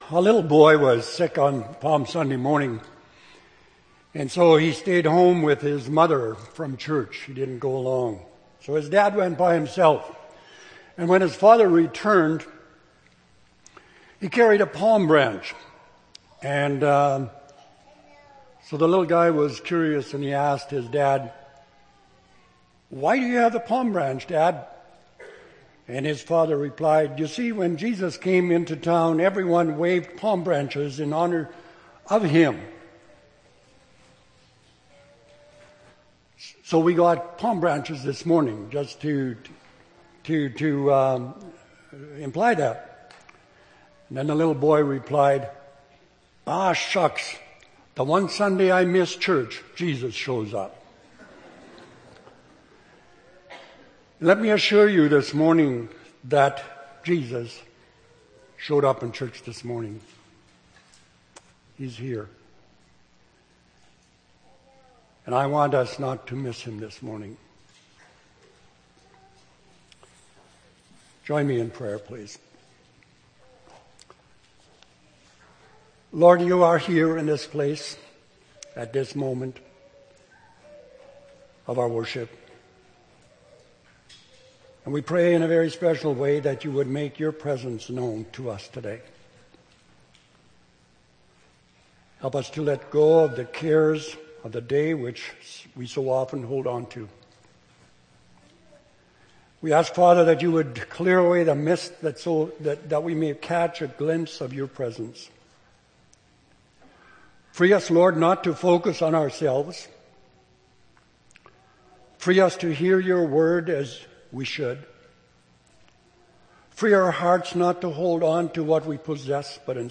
April 17, 2011 – Sermon